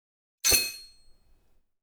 SwordSoundPack
SWORD_08.wav